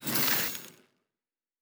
Door 7 Open.wav